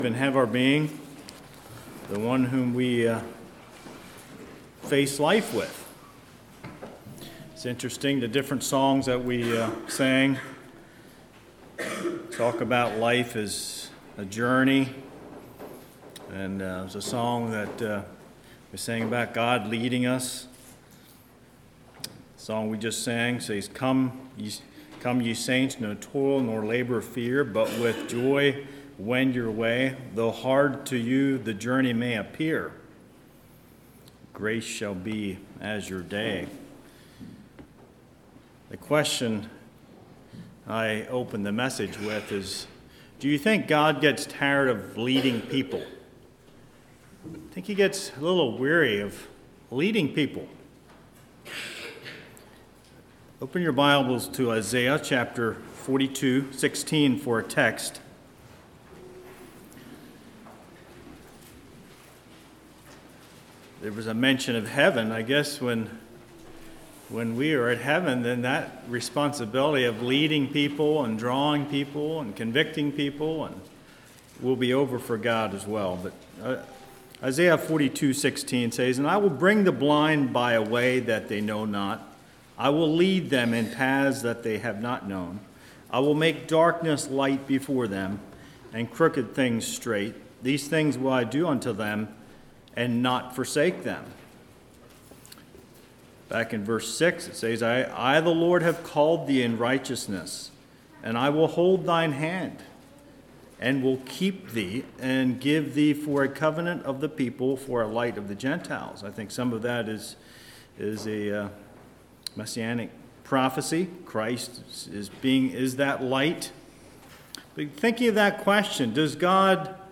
Kirkwood Speaker